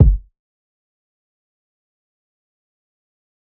Metro Kick [Dirty].wav